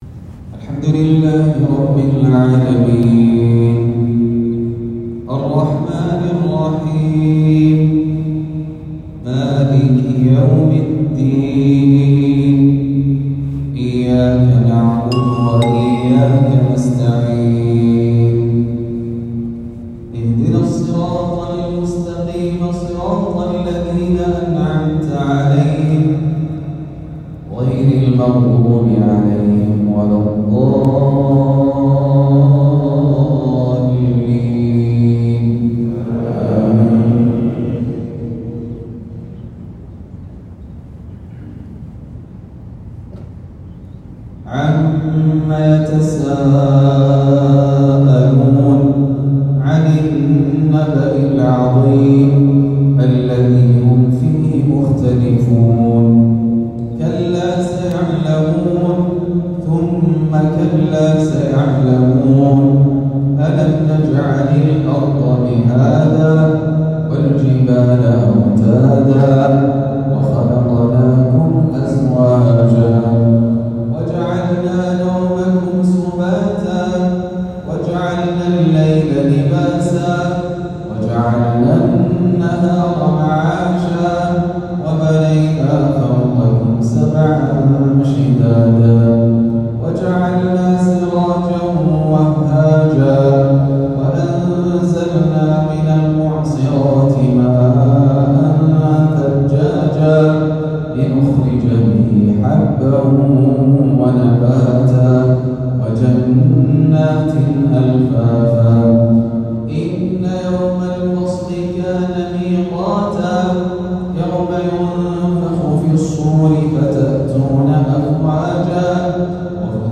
صلاة العشاء من جامع إمام الدعوة بمكة | السبت ٢٦ ذو القعدة ١٤٤٦هـ > الدورة العلمية الأولى لأئمة الحرمين الشريفين في دورة الحج لعام 1446هـ > الدورات العلمية لأئمة الحرمين الشريفين 🕋 > المزيد - تلاوات الحرمين